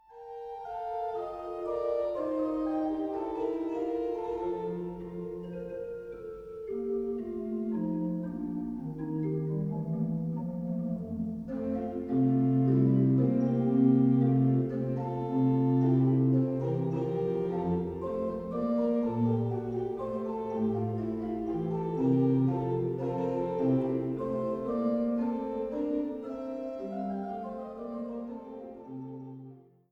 Eule-Orgel im Dom zu Zeitz
Orgel